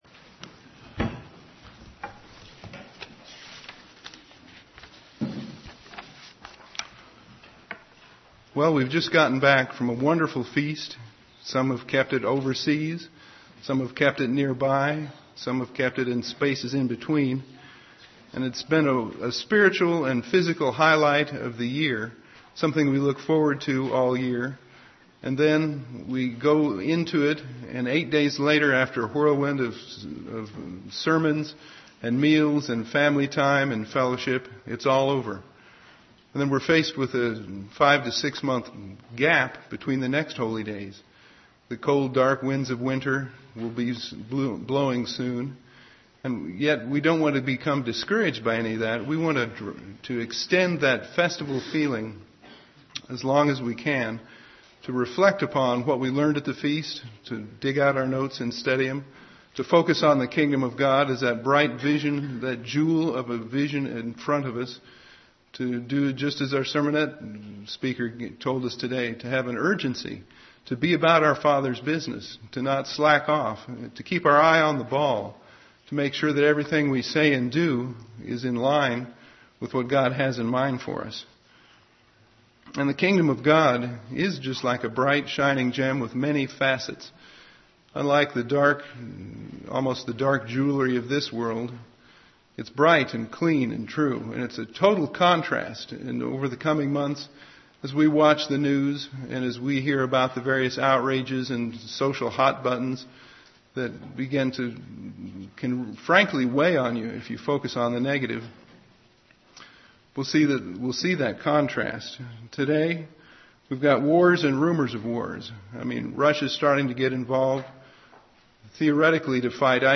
Given In Ft. Wayne, IN
UCG Sermon